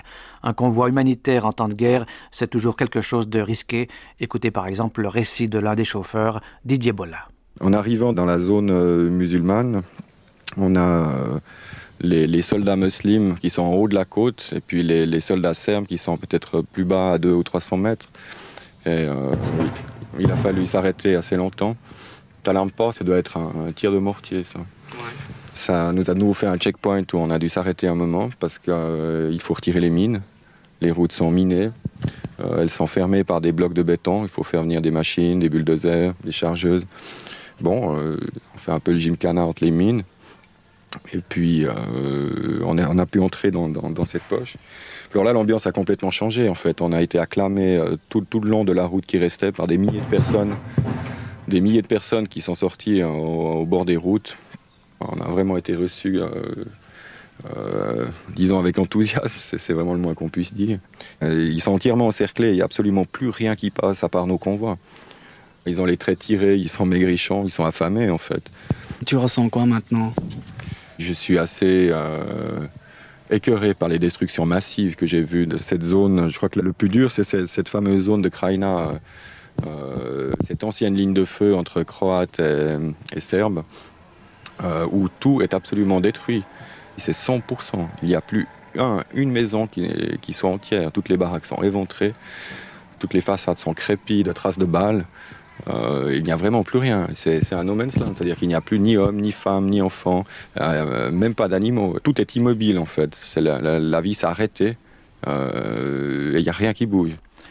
Les résultats de leur étude sont présentés dans l’exposition, où les visiteurs ont également l’opportunité de participer à l’expérience par l’écoute de témoignages enregistrés dans des contextes de guerre et par les émotions qu’ils suscitent.
Extrait d’un témoignage d’un chauffeur de convoi humanitaire du CICR.